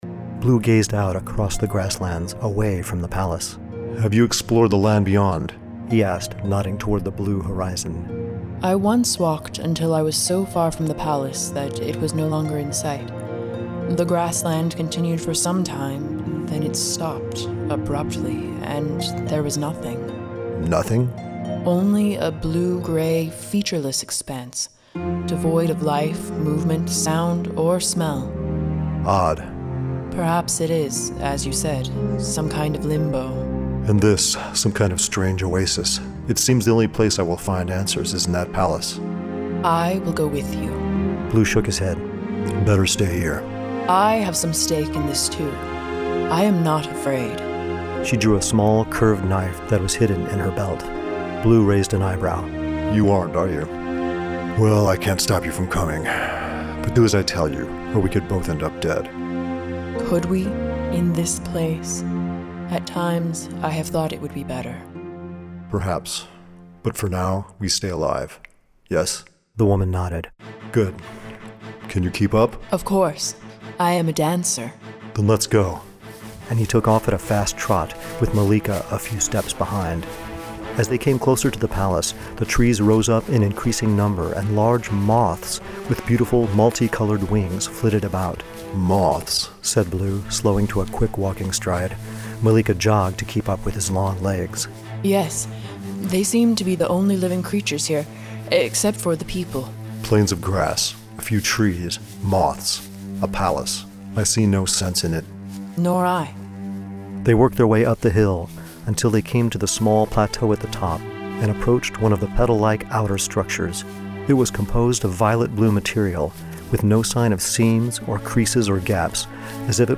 Dream Tower Media creates full audiobook dramas featuring professional voice actors, sound effects, and full symphonic musical scores.
Excerpts from The Blue Lamp audiobook drama